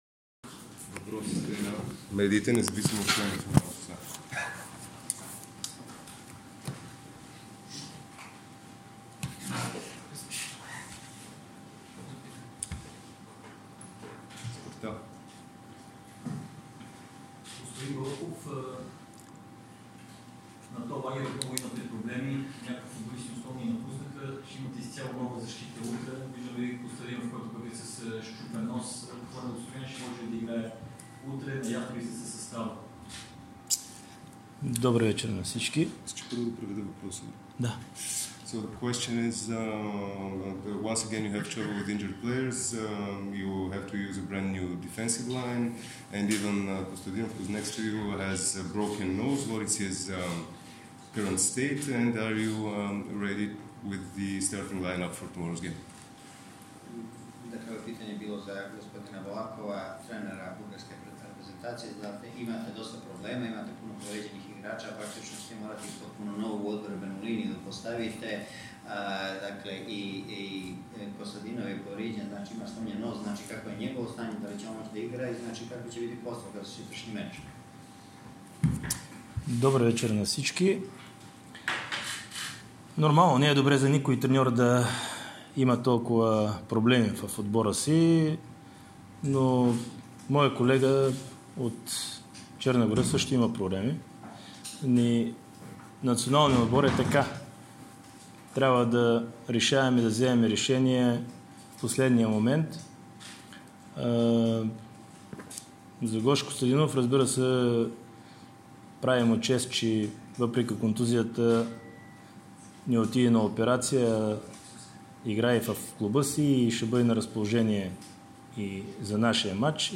10-BALAKOV-PRESS.m4a